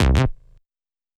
TI98BASS2 -R.wav